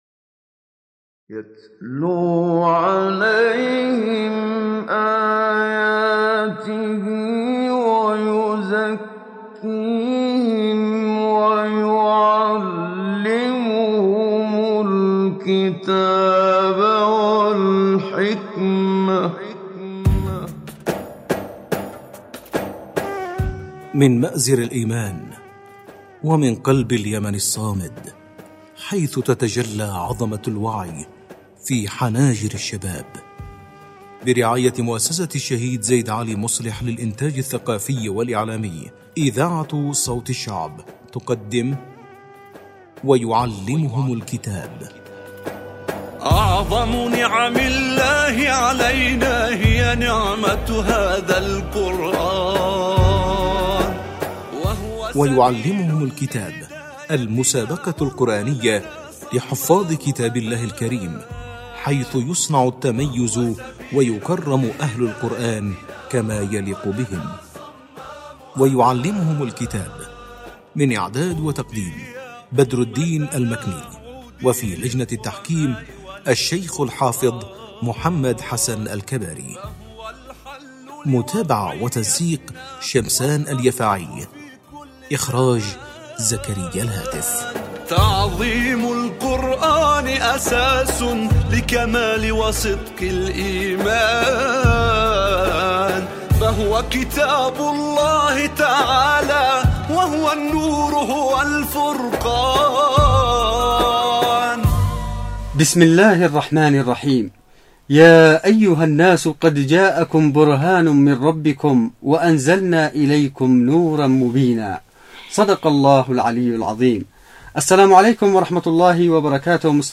مسابقة_القرآن_الكريم_ويعلمهم_الكتاب_14.mp3